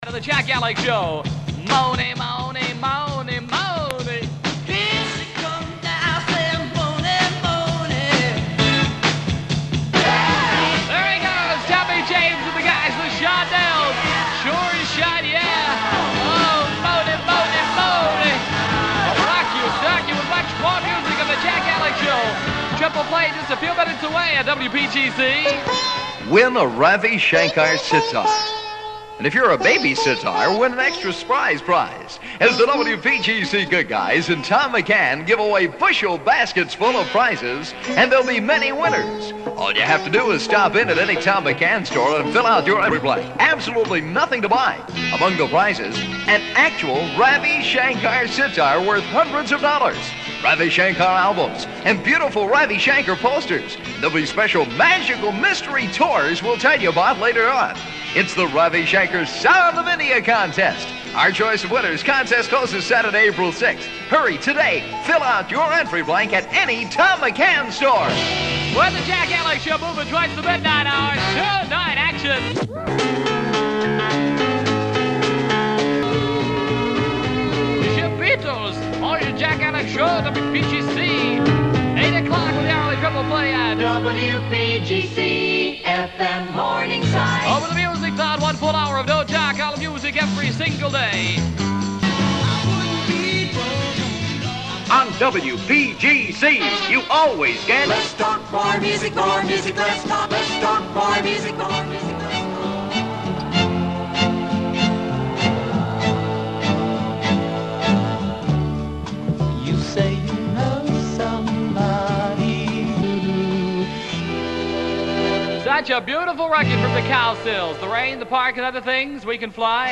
- A 'Thatman' jingle from 1966 made by Spot Productions of Dallas based on the then quickly waning, Bat-fad.